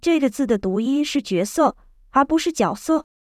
heteronym_audio_demo.wav